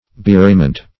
Bewrayment \Be*wray"ment\ (-ment), n.